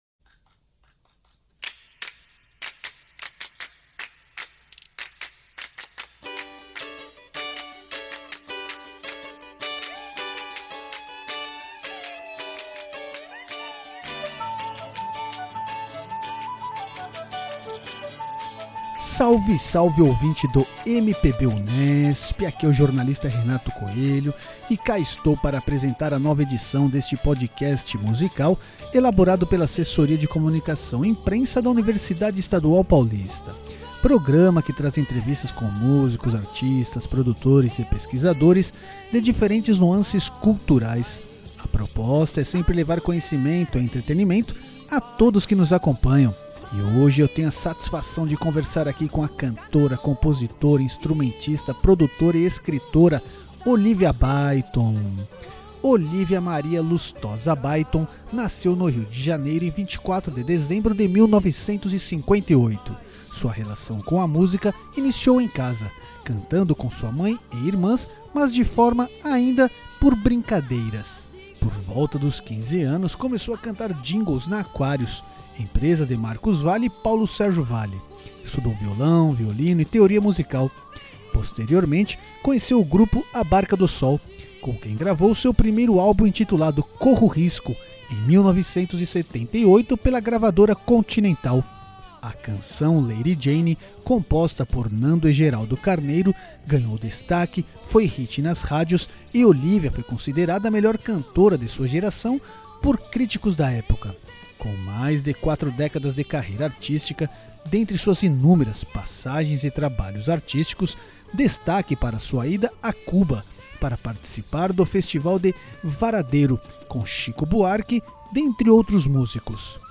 Destaque desta edição do MPB Unesp, a cantora, compositora e musicista, Olivia Byington , fala pontos relevantes de sua trajetória artística até os trabalhos atuais.
O PodMPB traz áudios de entrevistas com pesquisadores e músicos de diferentes gêneros, com a proposta de oferecer entretenimento e conhecimento ao ouvinte.